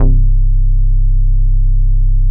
Index of /90_sSampleCDs/Best Service ProSamples vol.48 - Disco Fever [AKAI] 1CD/Partition D/SYNTH-BASSES